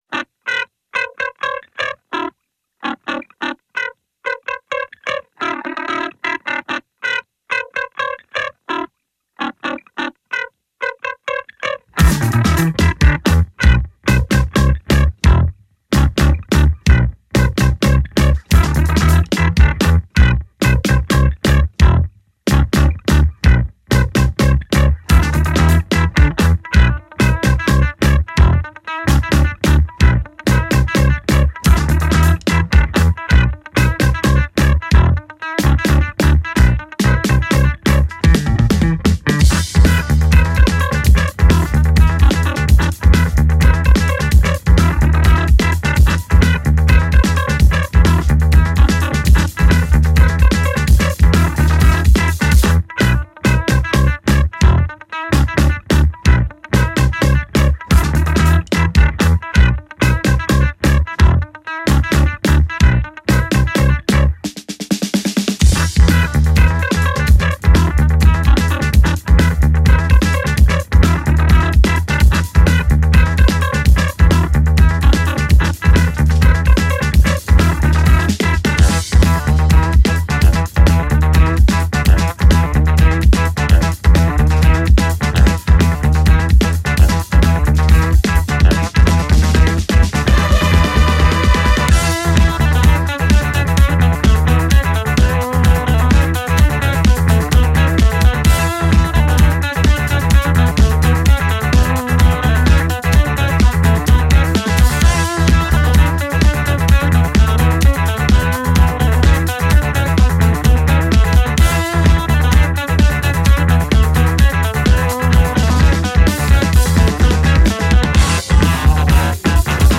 La Recensione Heavy metal per adulti?
In questa recensione del secondo album del duo, appena uscito, eviteremo di ripetere quanto è già stato abbondantemente scritto. Ma, grazie alle risorse e alla cultura musicale curiosa di Rete Due, potremo offrire ai nostri lettori e ascoltatori quello che le decine o centinaia di commentatori non hanno potuto dare: la musica!